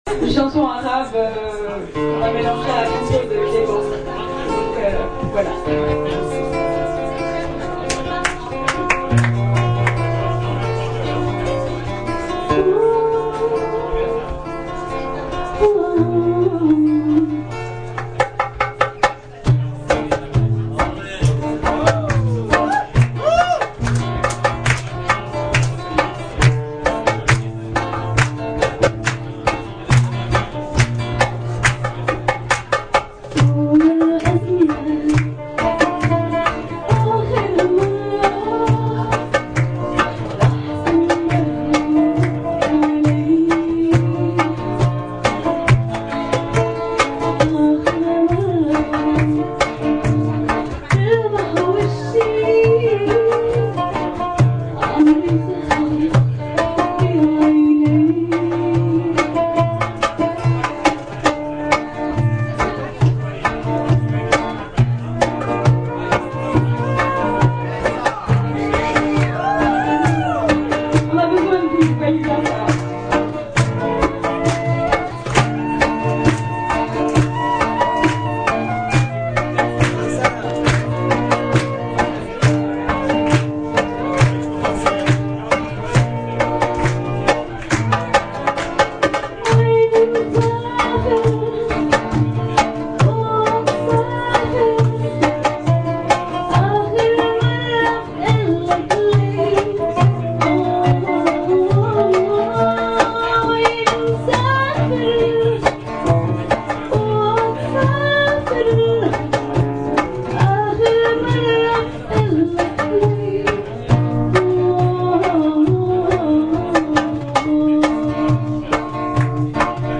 ! Extraits du concert au format mp3 !